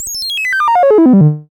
SI2 CHAIN.wav